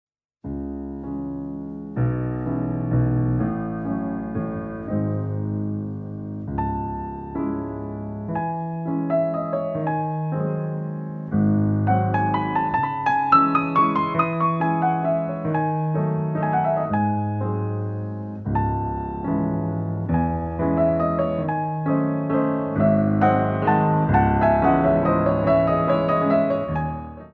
Compositions for Ballet Class
Ronds de jambe à terre